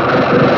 8 Boiling In Dust Noisy Guitar 2.wav